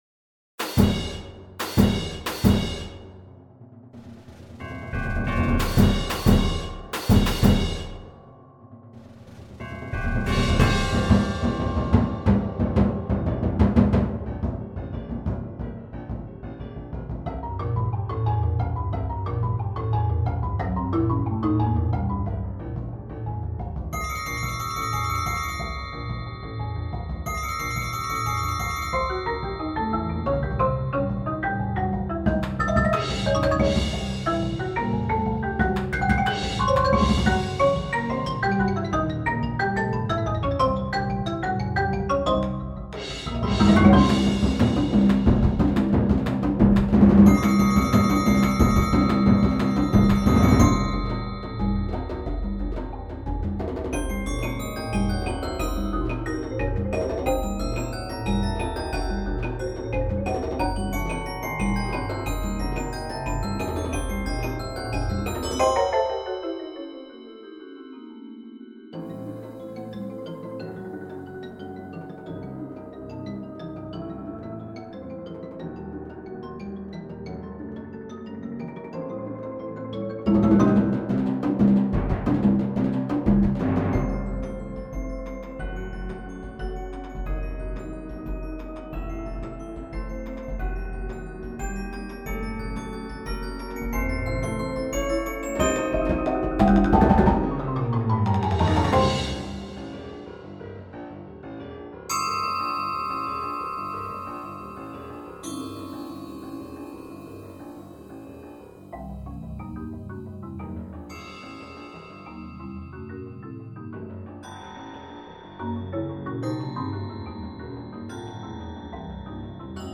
Genre: Percussion Ensemble
# of Players: 13
Player 1: Bells
Player 2: Xylophone, Chimes, Crotales (2 octaves)
Player 3: Vibraphone, Suspended Cymbal
Player 9: 4 Timpani, Claves, Sizzle Ride Cymbal